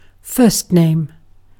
Ääntäminen
IPA: [pʁe.nɔ̃]